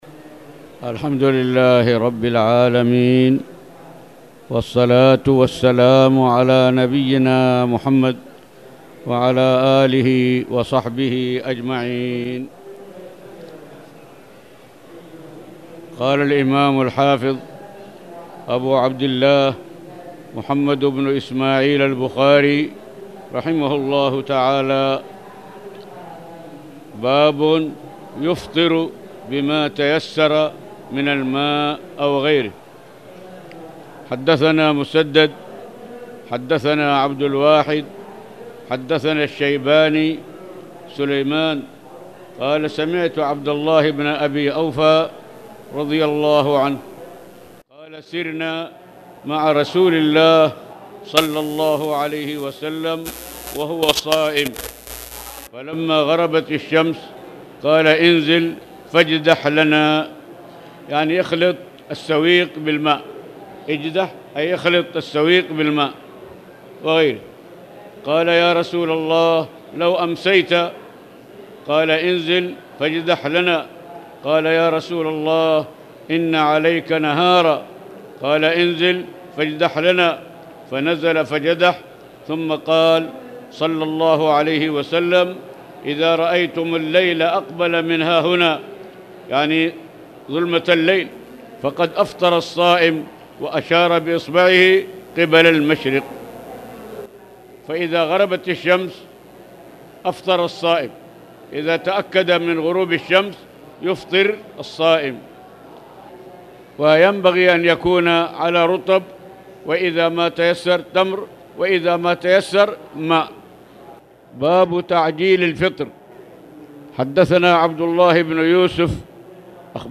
تاريخ النشر ٢٩ ربيع الأول ١٤٣٨ هـ المكان: المسجد الحرام الشيخ